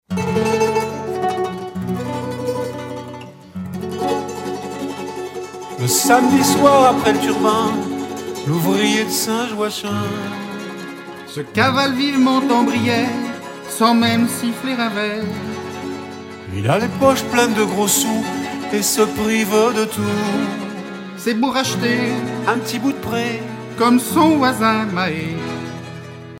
sur un timbre
Pièce musicale éditée